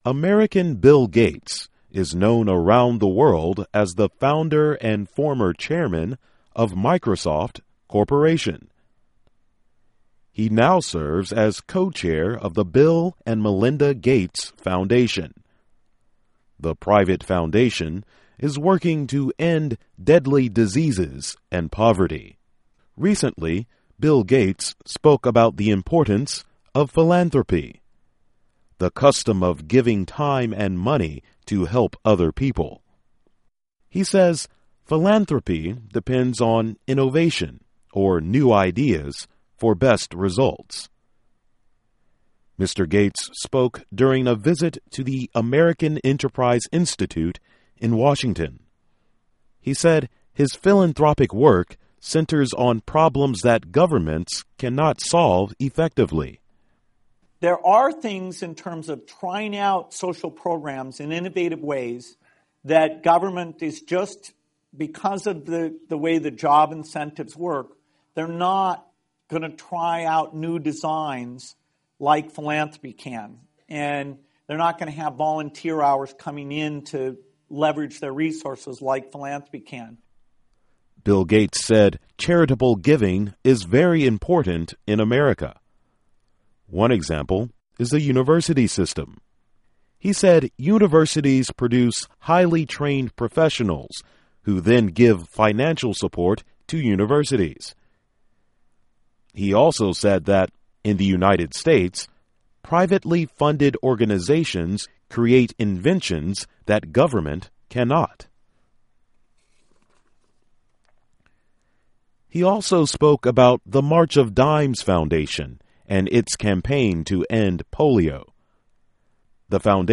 Bill Gates speaking at a philanthropy event.